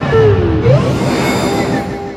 Cri de Bamboiselle dans Pokémon Soleil et Lune.